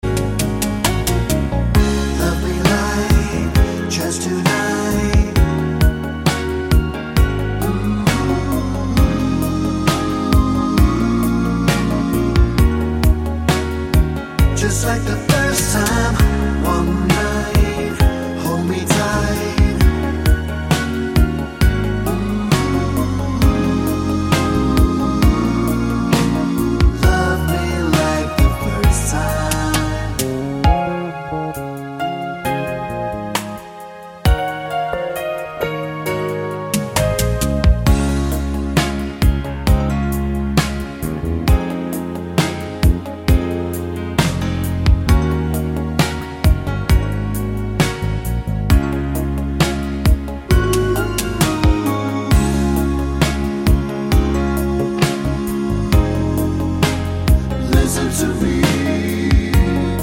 no Backing Vocals Soul / Motown 3:20 Buy £1.50